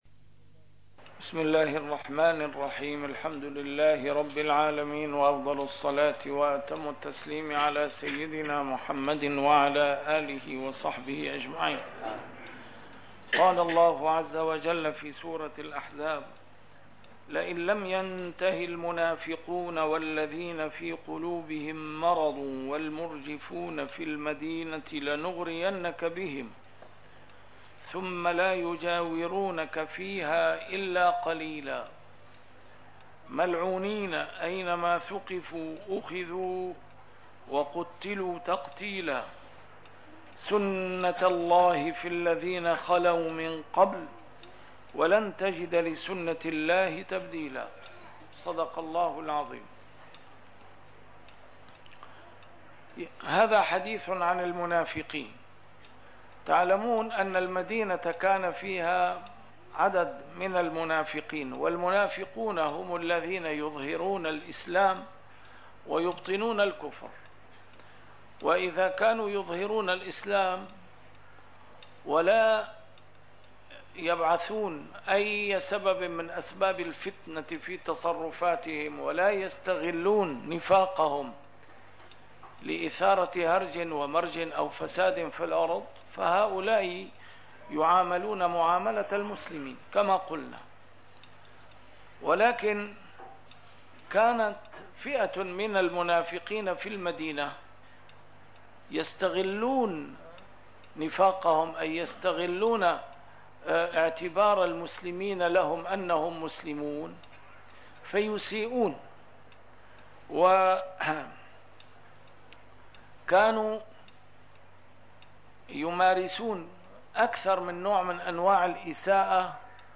A MARTYR SCHOLAR: IMAM MUHAMMAD SAEED RAMADAN AL-BOUTI - الدروس العلمية - تفسير القرآن الكريم - تسجيل قديم - الدرس 383: الأحزاب 60-63